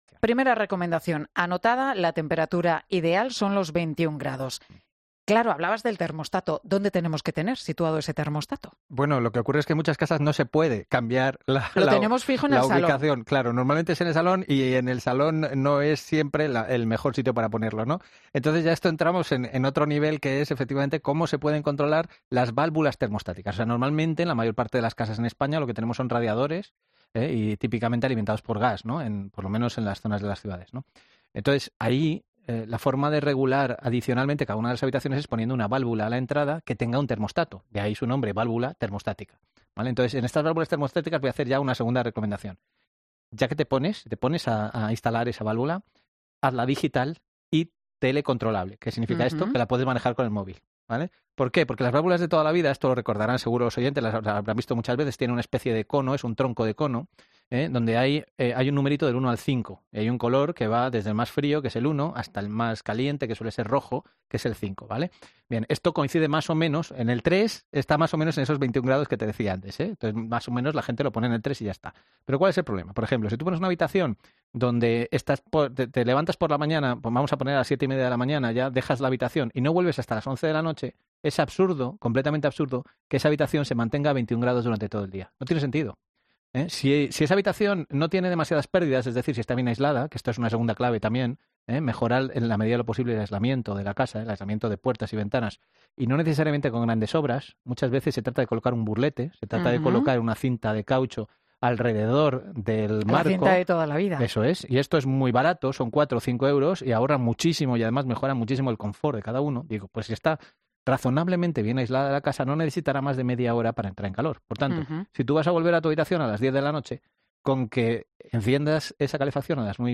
Ante la duda de un oyente sobre si es mejor apagar por completo la calefacción al salir de casa o mantenerla a una temperatura baja, la respuesta del experto ha sido categórica.